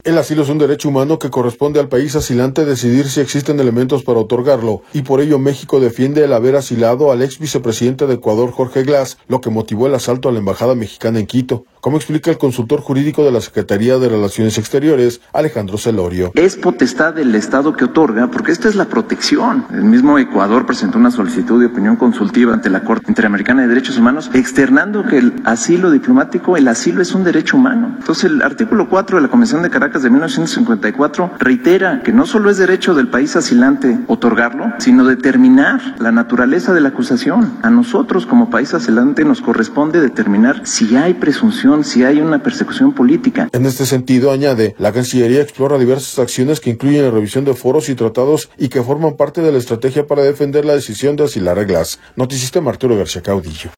El asilo es un derecho humano que corresponde al país asilante decidir si existen elementos para otorgarlo, y por ello México defiende el haber asilado al ex vicepresidente de Ecuador, Jorge Glas, lo que motivó el asalto a la embajada mexicana en Quito, como explica el consultor jurídico de la Secretaría de Relaciones Exteriores, Alejandro Celorio.